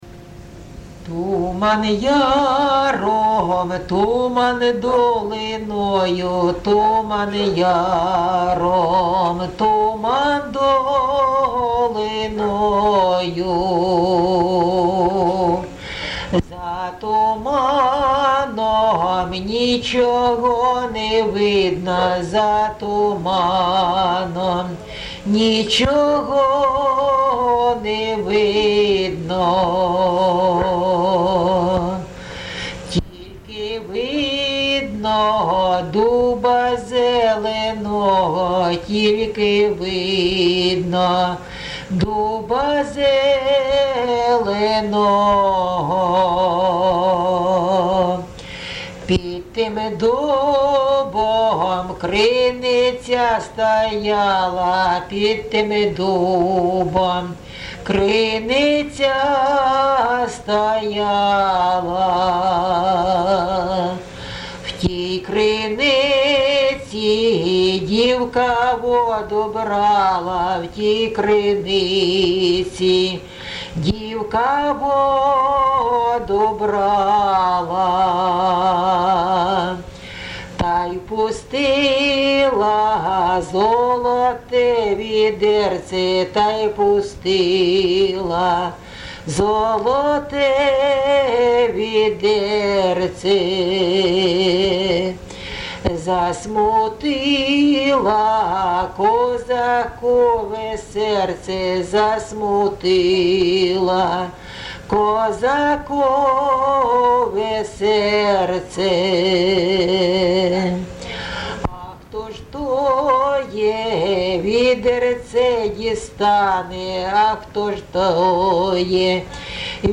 ЖанрПісні з особистого та родинного життя
Місце записус. Михайлівка, Олександрівський (Краматорський) район, Донецька обл., Україна, Слобожанщина